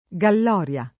galloria [ g all 0 r L a ] s. f.